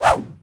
footswing7.ogg